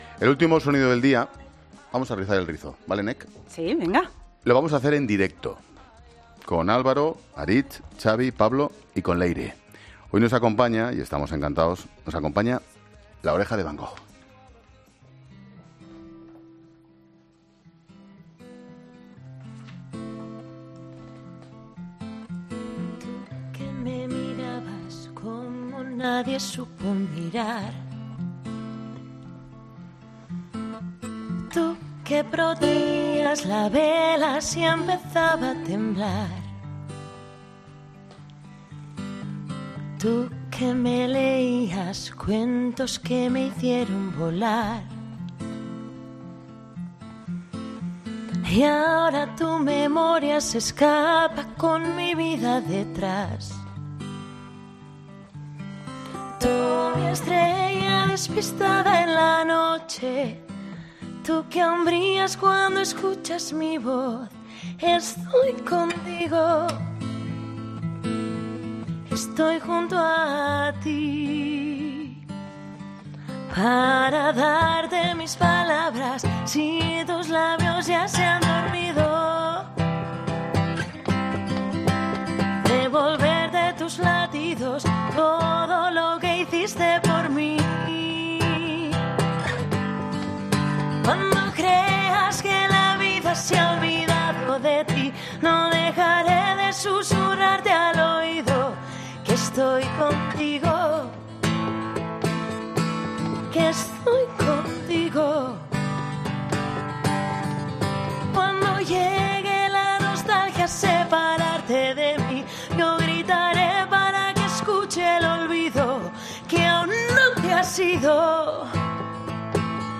Escucha la entrevista a La Oreja de Van Gogh en 'La Tarde'
La oreja de Van Gogh en directo en La Tarde de Cope